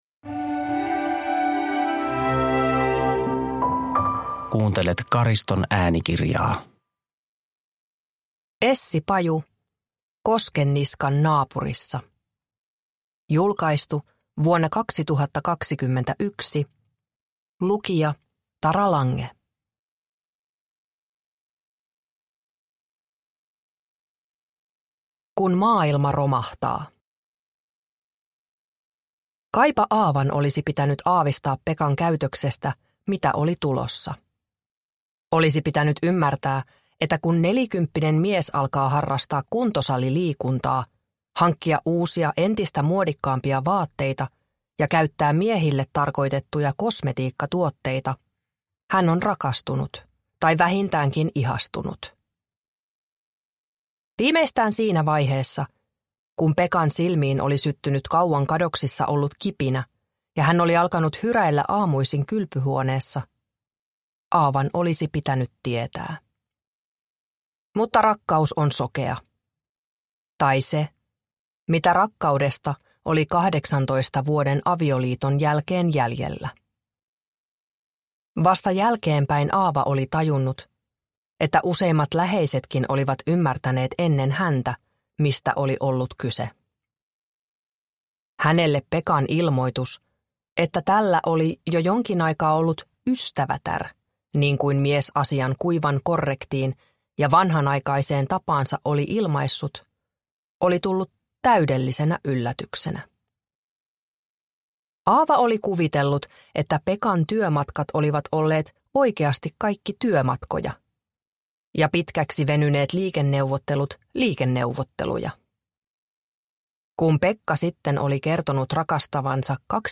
Koskenniskan naapurissa – Ljudbok – Laddas ner